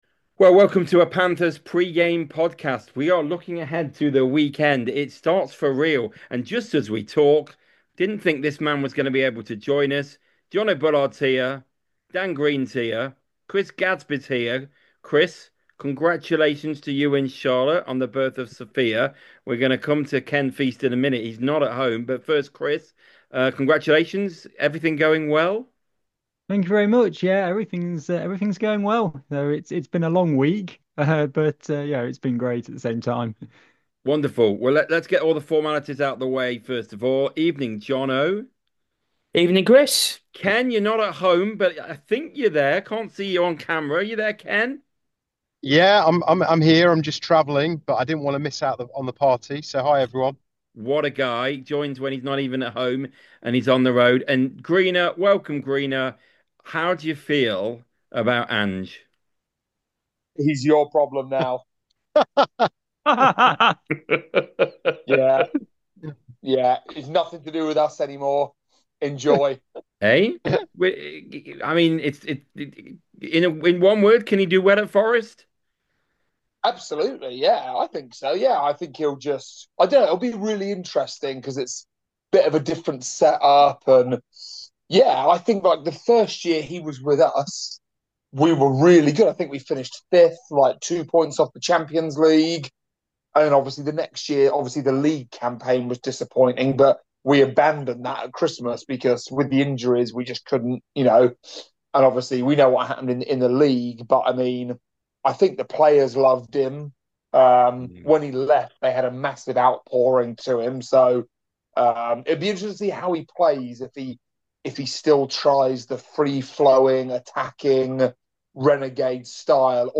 The podcast crew reflect on the pre-season campaign and discuss the big moments of the five matches so far. The five guys also look ahead to the 2025-26 campaign and their thoughts on what might be to come.